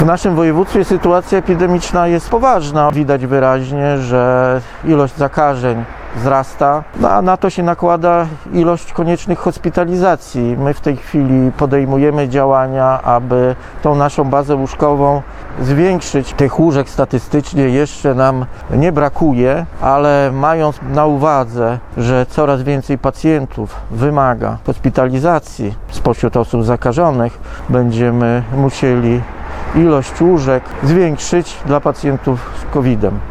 – Łóżek jeszcze nie brakuje, ale sytuacja epidemiczna w naszym województwie jest poważna – mówi Bohdan Paszkowski, wojewoda podlaski.
wojewoda1.mp3